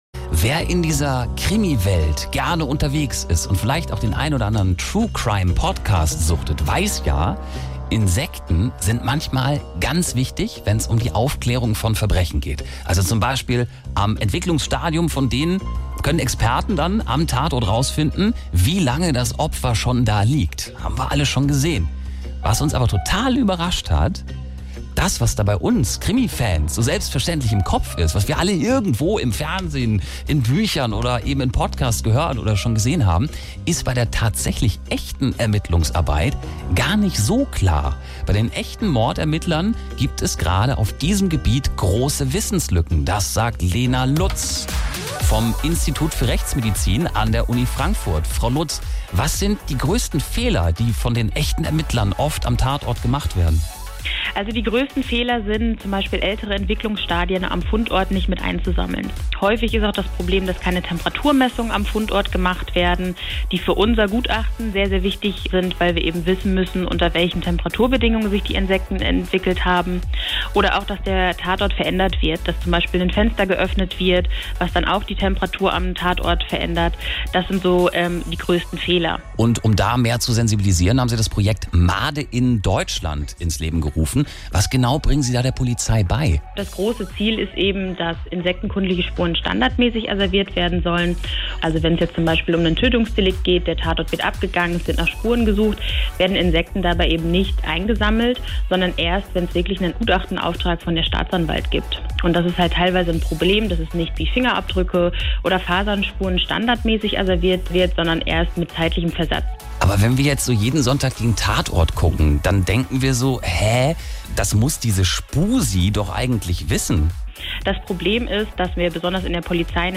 SWR3-Moderator
Interview